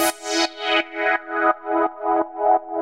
Index of /musicradar/sidechained-samples/170bpm
GnS_Pad-alesis1:4_170-E.wav